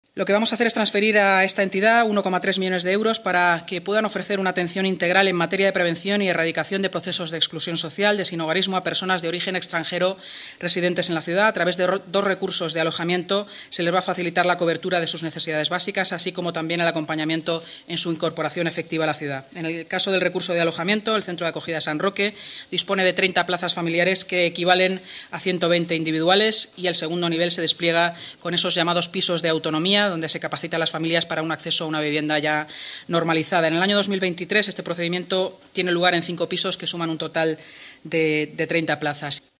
Nueva ventana:Así lo ha explicado la vicealcaldesa y portavoz municipal, Inma Sanz, tras la Junta de Gobierno: